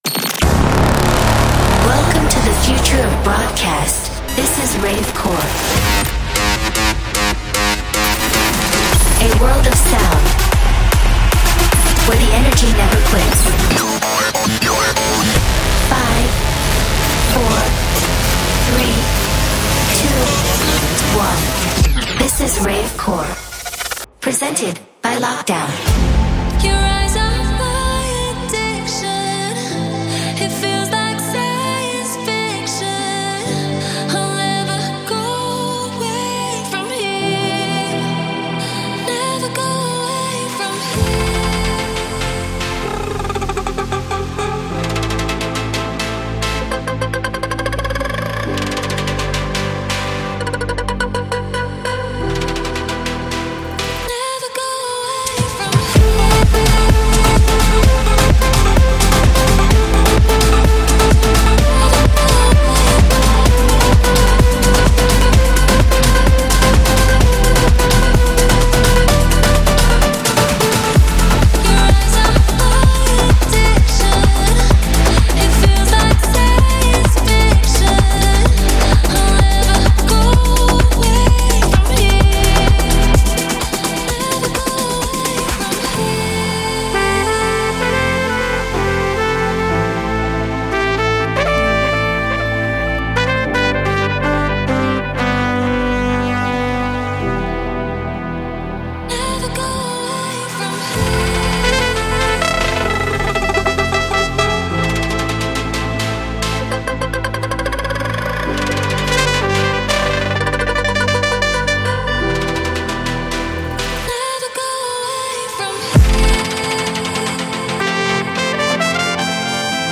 House mix